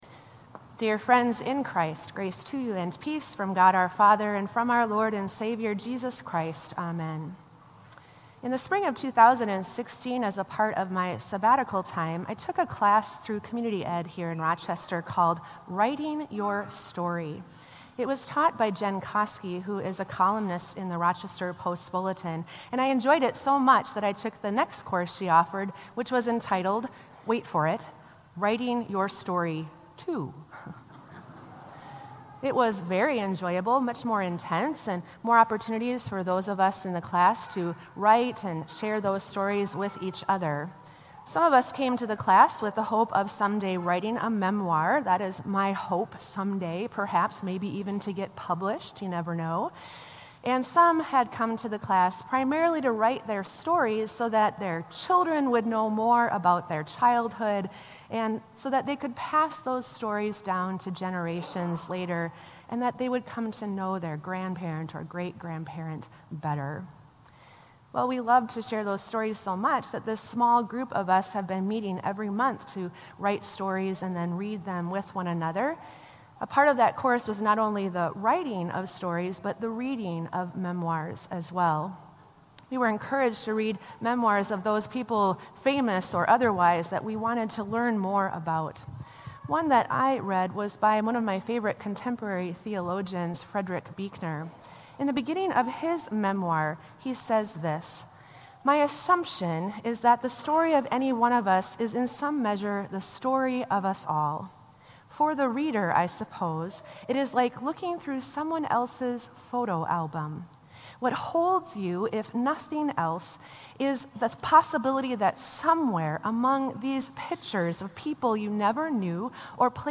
Sermon “Tell Me the Story”